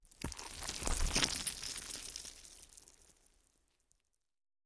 Коллекция включает аудиоэффекты для создания атмосферы хоррора: хруст костей, скрип скальпеля, всплески жидкостей.
Звук: вырываем кишки из тела голыми руками